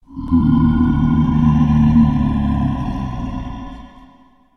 stereo audio to mono
spawners_mobs_mummy.1.ogg